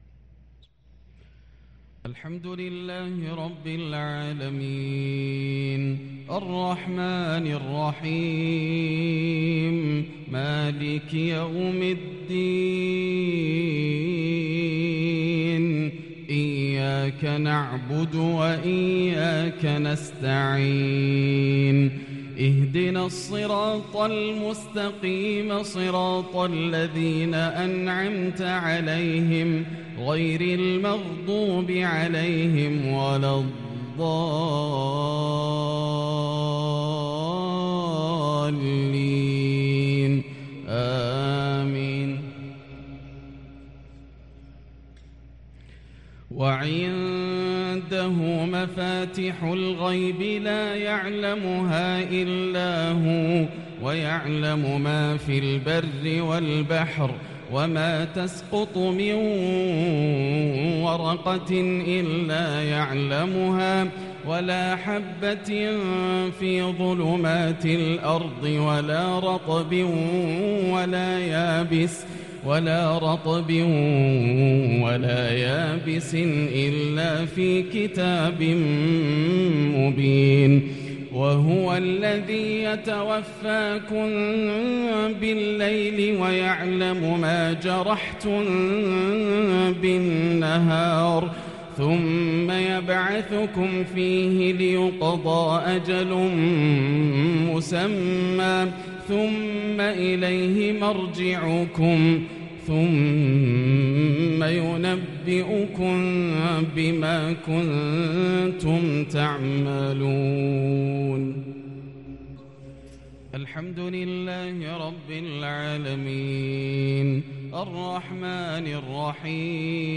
مغرب الخميس 5-2-1444هـ من سورة الأنعام | Maghrib prayer from surah Al-Anam 1-9-2022 > 1444 🕋 > الفروض - تلاوات الحرمين